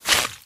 脚步声
CR_fm_step_02.mp3